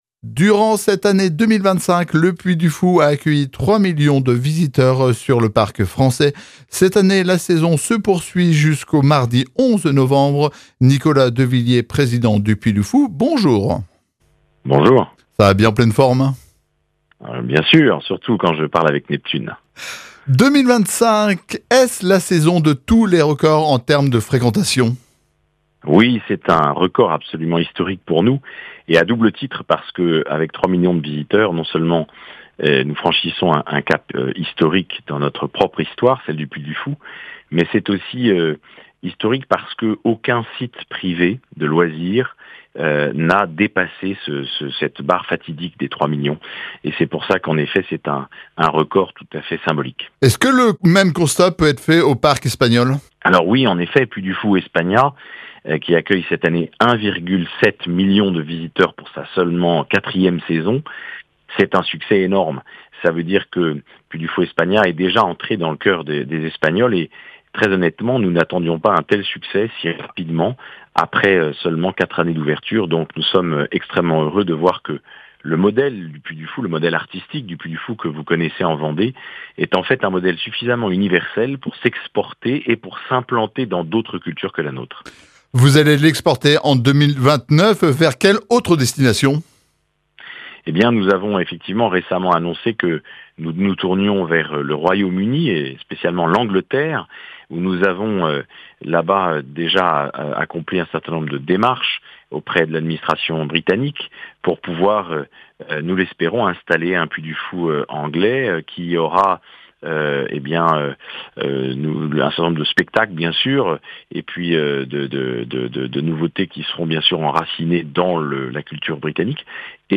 Fort de plus de trois millions de visiteurs en 2025, le Puy du Fou voit grand et veut rayonner bien au-delà de la Vendée ! Son président Nicolas de Villiers nous dévoile une série de projets ambitieux : un grand spectacle de Noël dès 2026, un voyage en train d'époque à travers la France, une tournée des Zénith en 2027 et un nouveau parc au Royaume-Uni prévu pour 2029, après celui de Tolède ouvert en 2021... rien que ça !